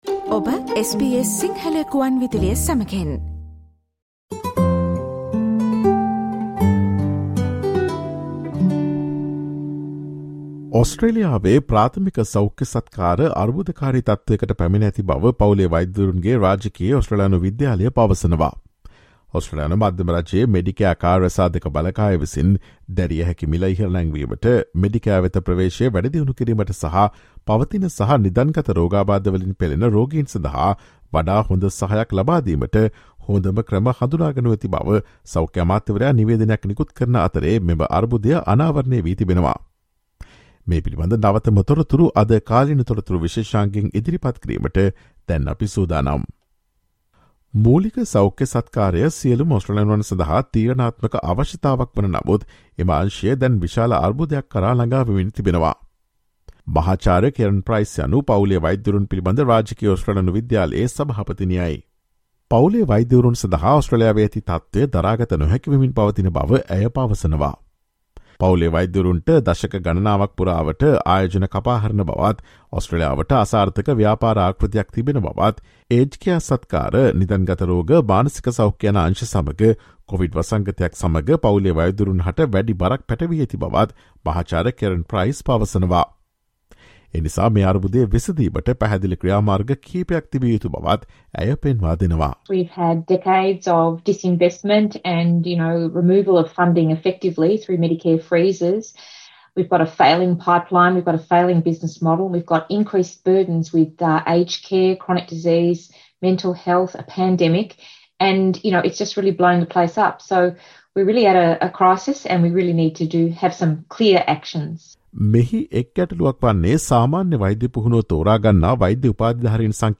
Listen to the SBS Sinhala Radio's current affairs feature broadcast on Thursday 18 August with information about what the Royal Australian College of General Practitioners says Australia's primary care is in crisis.